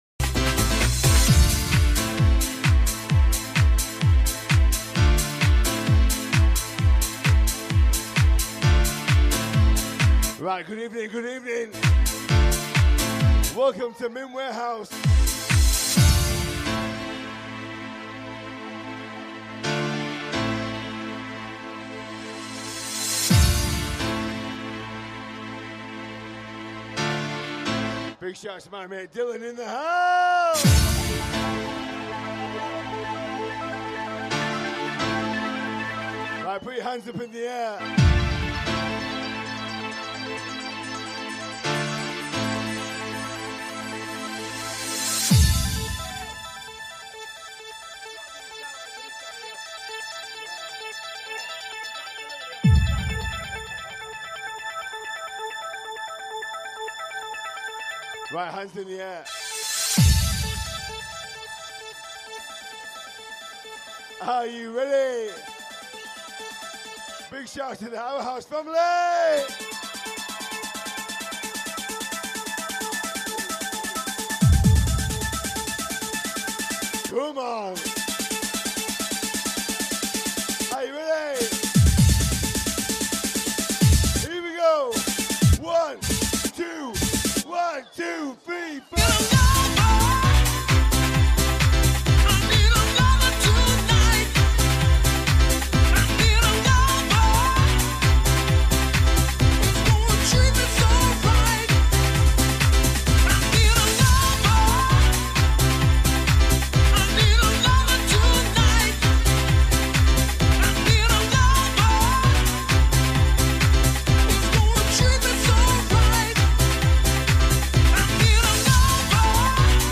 Our House LIVE From Mint Warehouse 4-9-21 Part 2